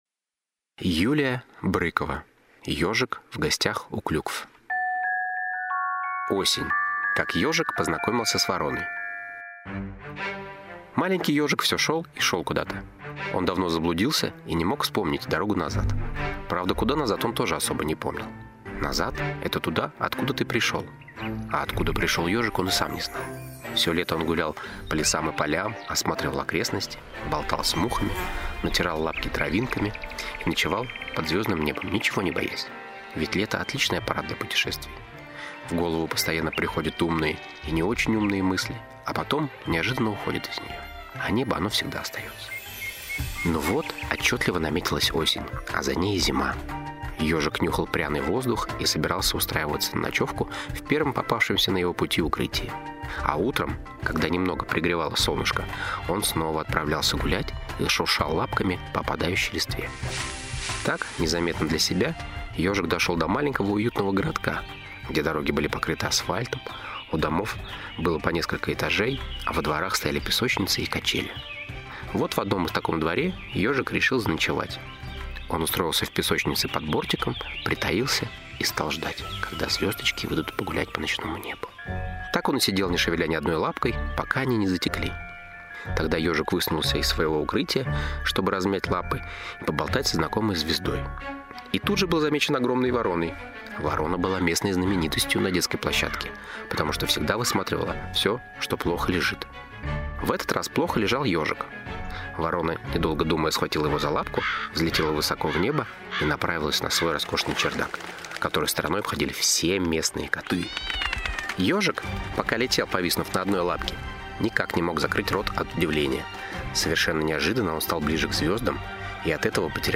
Аудиокнига Ёжик в гостях у Клюкв | Библиотека аудиокниг